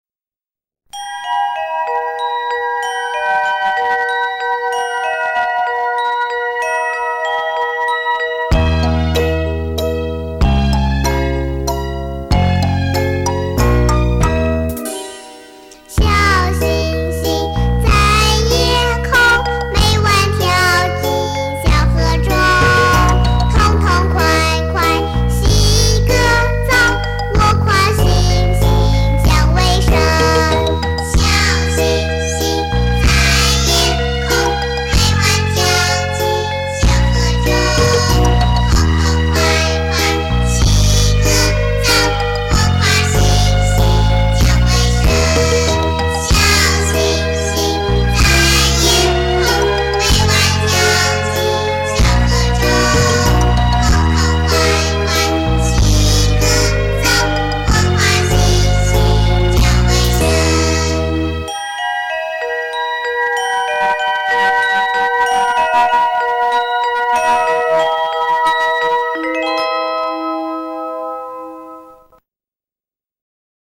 背景音乐
【KA03】儿童歌曲